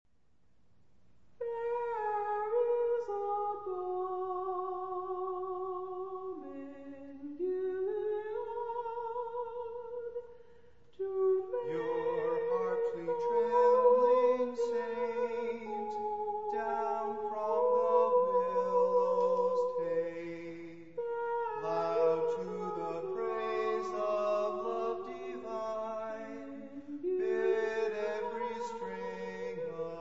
For four vocalists and chamber orchestra